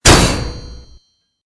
CHQ_FACT_stomper_small.ogg